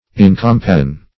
Search Result for " incompassion" : The Collaborative International Dictionary of English v.0.48: Incompassion \In`com*pas"sion\, n. [Pref. in- not + compassion: cf. F. incompassion.] Lack of compassion or pity.